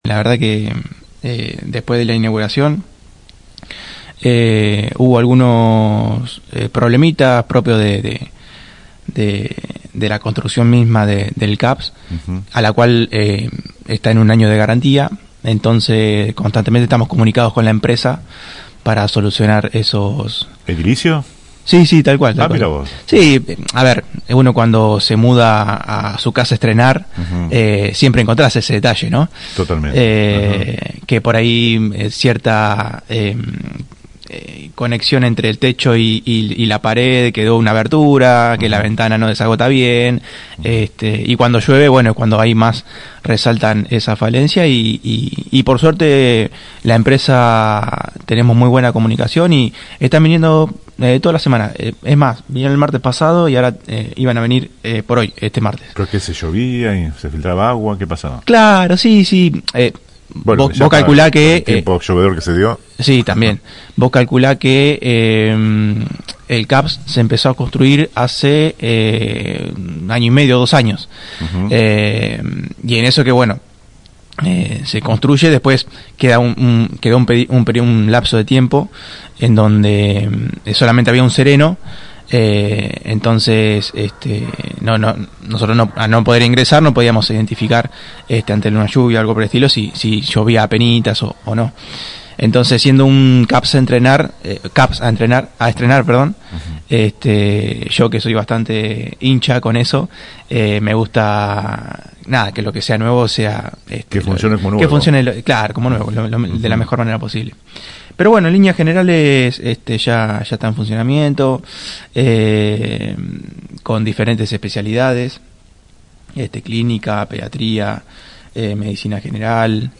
Conversamos esta mañana con el farmacéutico a cargo de la cartera de salud del municipio de Las Flores Eduardo Zapata. En el abanico de preguntas, en el inicio se refirió al debut del Caps Héctor Muñiz: